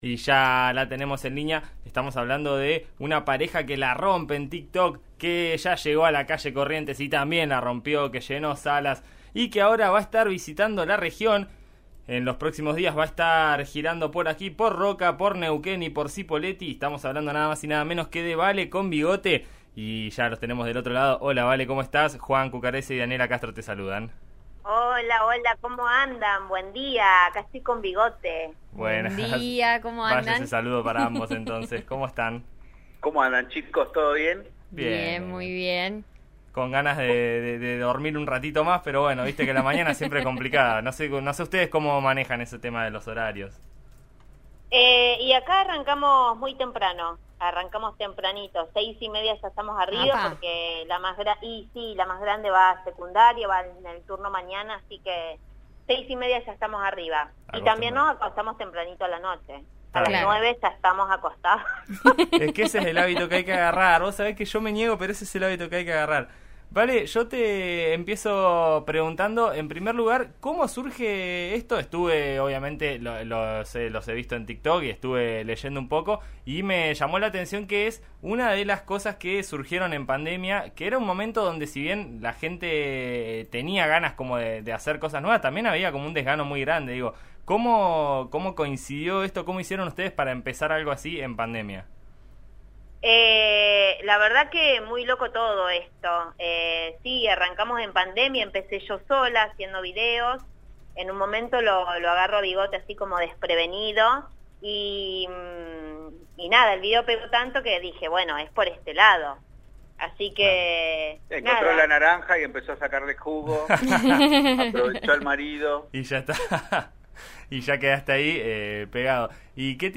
Cómo surgió esta dinámica y qué los llevó a subirse al escenario. Escuchá la nota al aire de 'En Eso Estamos', por RN RADIO, acá.